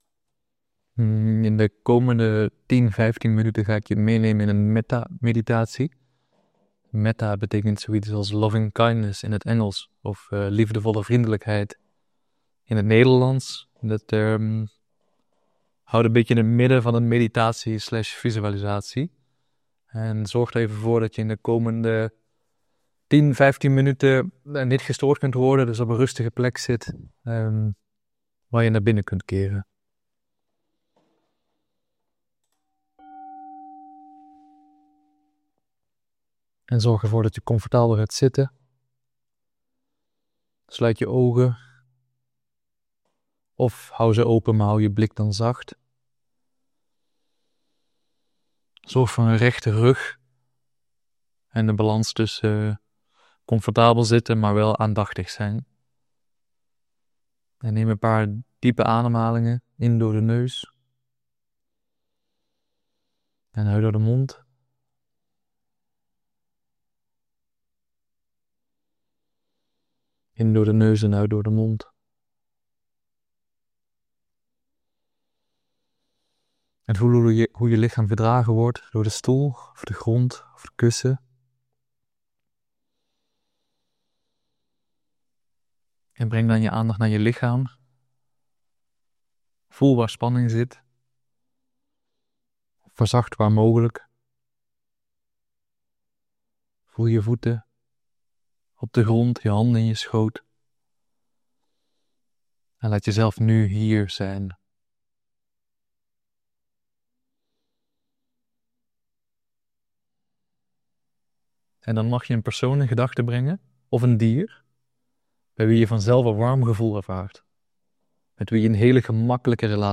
Een geleide meditatie in liefdevolle vriendelijkheid (Metta, of Loving Kindness). Door te oefenen met diverse visualisaties, leer je om warme gevoelens te creëren voor de ander en voor jezelf.